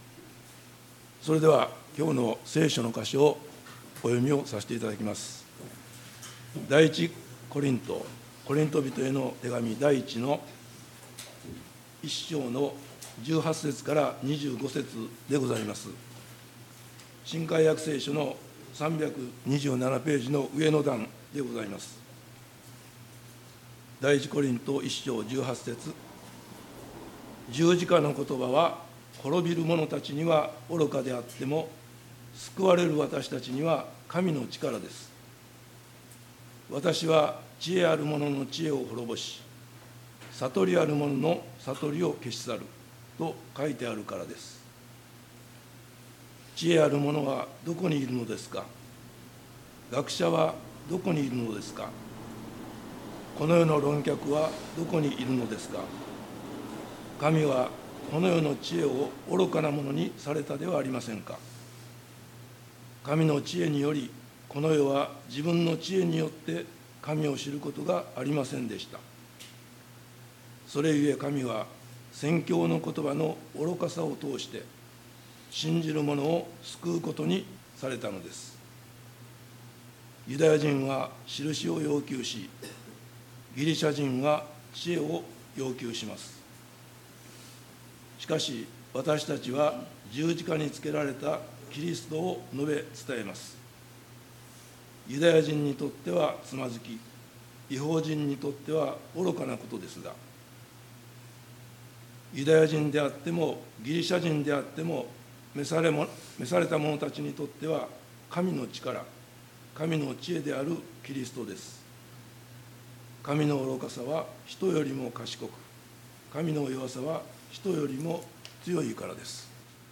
礼拝メッセージ「十字架、神の力」│日本イエス・キリスト教団 柏 原 教 会